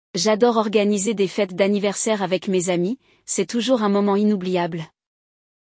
O Walk ‘n’ Talk Level Up é um podcast imersivo para quem deseja aprender francês de forma dinâmica, ouvindo diálogos autênticos e naturais.